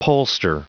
Prononciation du mot pollster en anglais (fichier audio)
Prononciation du mot : pollster